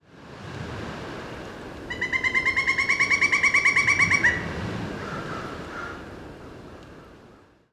Pileated Woodpecker – Dryocopus pileatus
‘Whinny’ Call Parc national du Bic, QC, Rimouski-Neigette, QC.